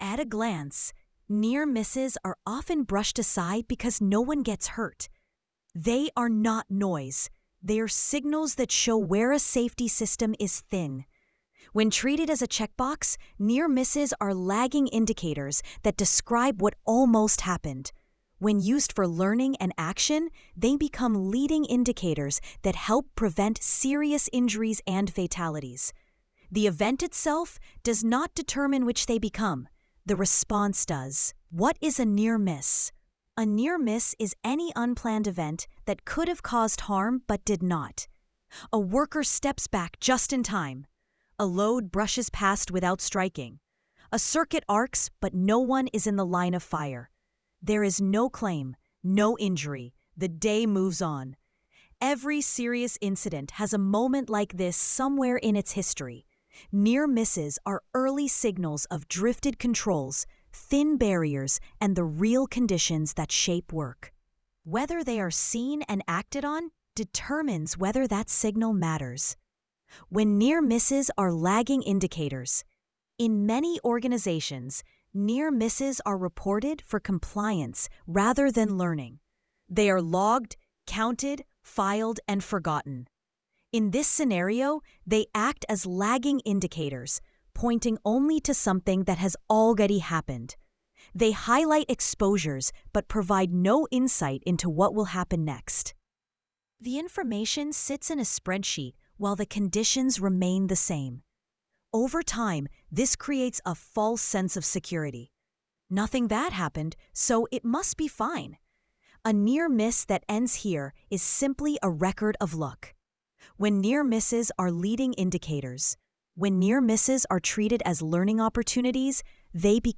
sage_gpt-4o-mini-tts_1x_2025-10-30T03_42_15-781Z.wav